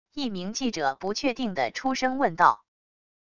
一名记者不确定的出声问道wav音频